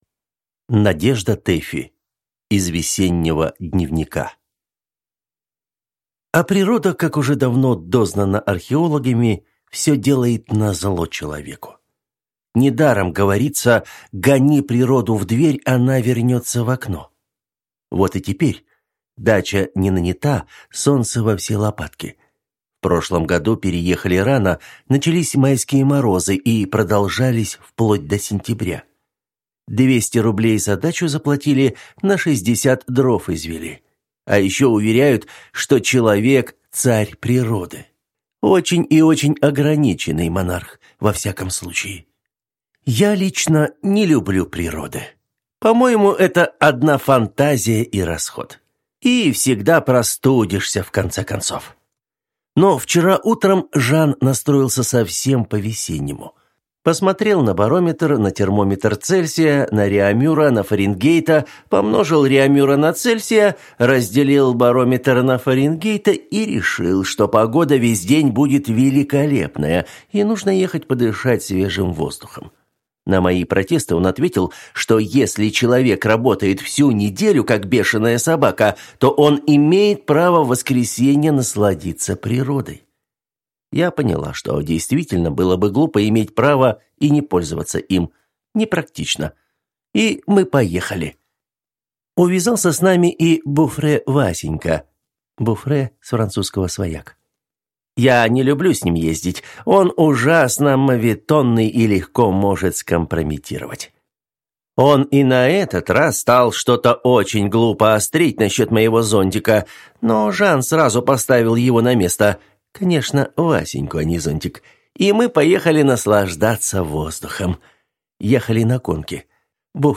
Аудиокнига Из весеннего дневника | Библиотека аудиокниг